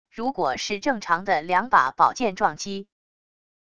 如果是正常的两把宝剑撞击wav音频